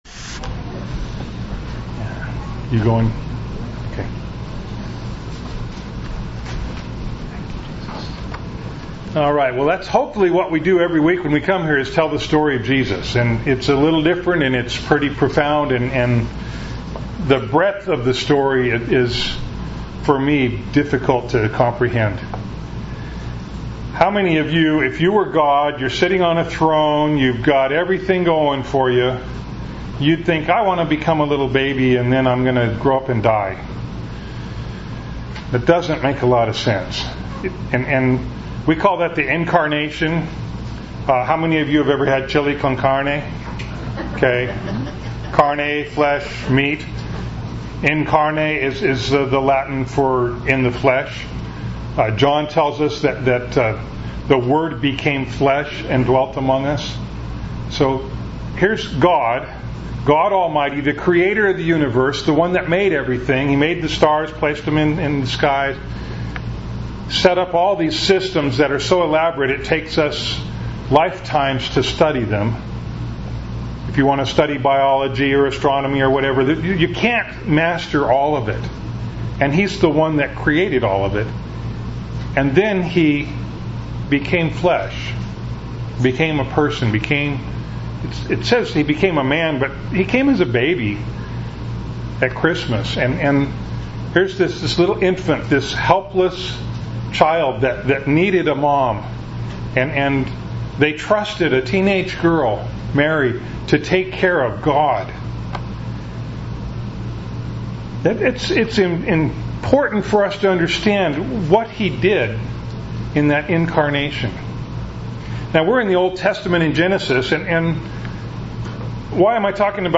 Bible Text: Genesis 32:22-32 | Preacher